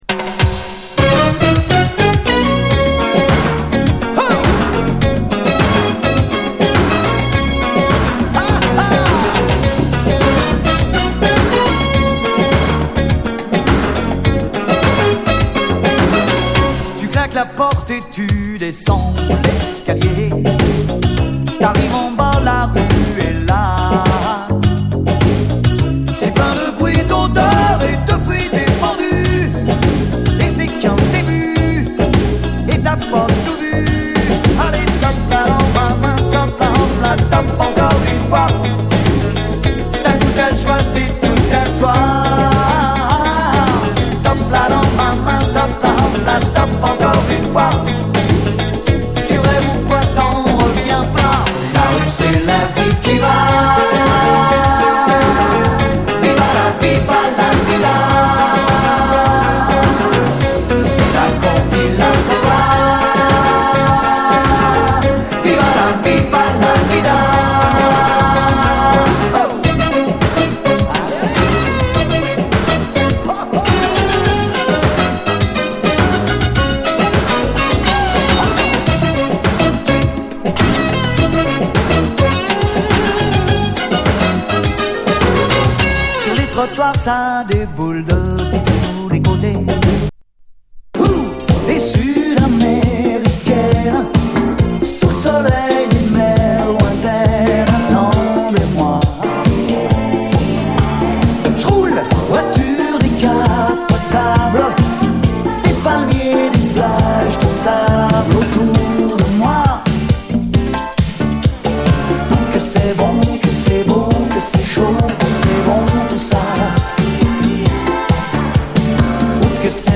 SOUL, 70's～ SOUL, DISCO, WORLD, FUNK-A-LATINA